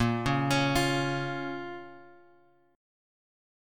Asharp-Minor-Asharp-6,4,x,6,6,x-8.m4a